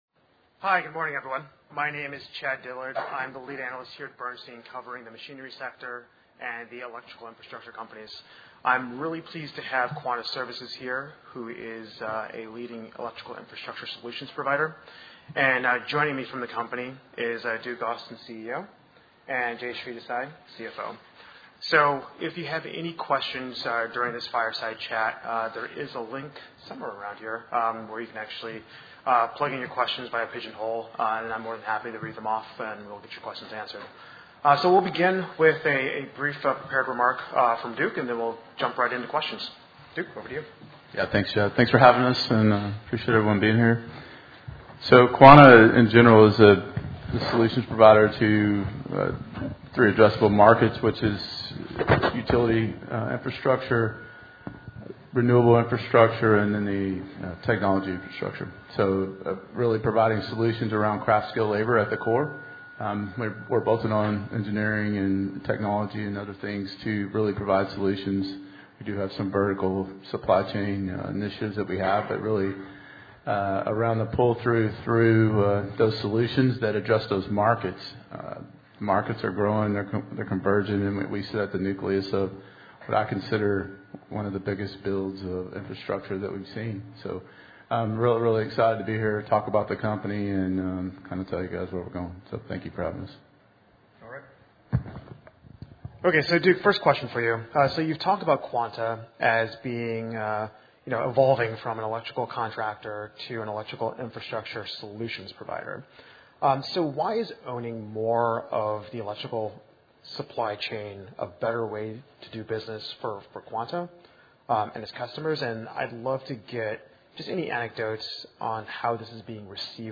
PWR+Bernstein+Fireside+Chat+5-28-25.mp3